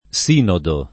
S&nodo] s. m. — lett. l’uso come s. f. (nel solo sing.), sul modello del gr. σύνοδος / sýnodos, che è femm., e sull’analogia di parodo, che però appartiene a una terminologia teatrale tutta greca — pl. i sinodi (non le sinodo) — cfr. anodo